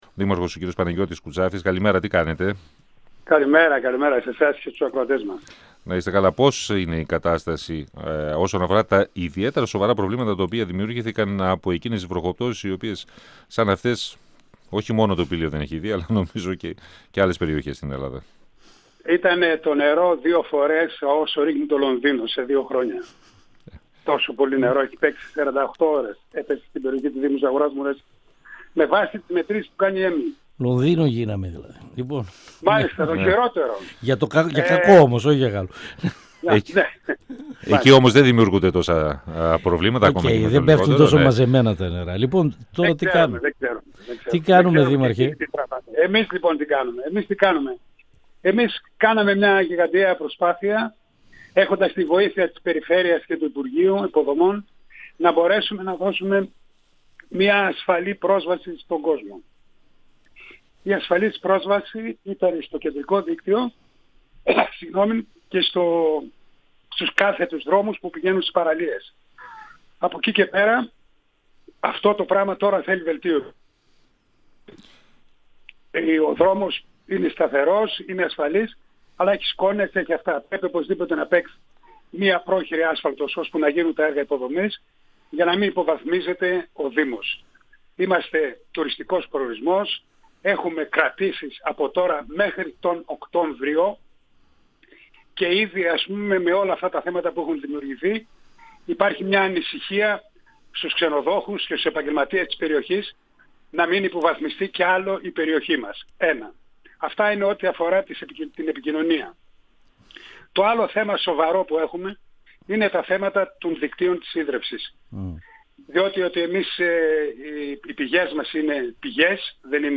Ο δήμαρχος Ζαγοράς – Μουρεσίου, Παναγιώτης Κουτσάφτης, στον 102FM του Ρ.Σ.Μ. της ΕΡΤ3
Συνέντευξη